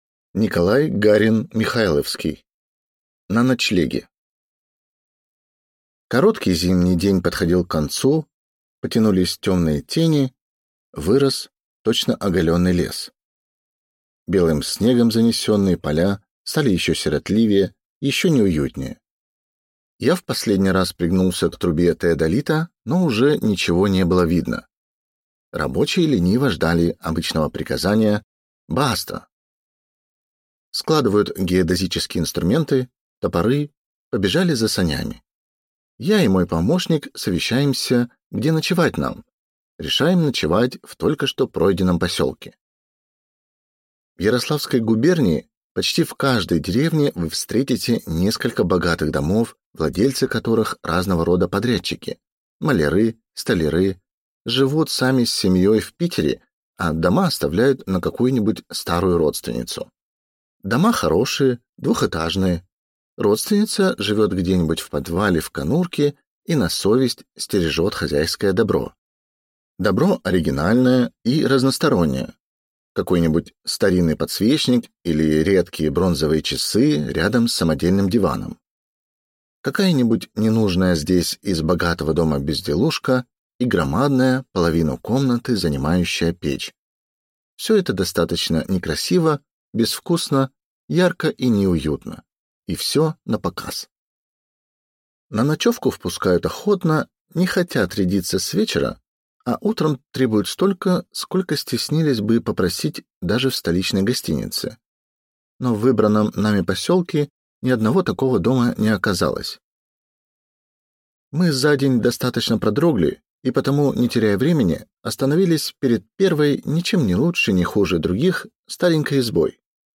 Аудиокнига На ночлеге | Библиотека аудиокниг